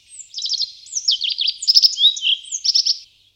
Bruant fou
Emberiza cia